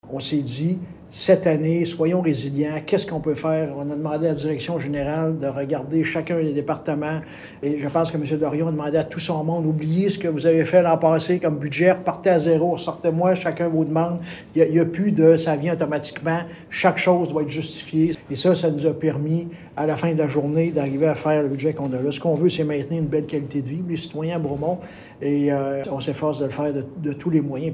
Louis Villeneuve, Maire de Bromont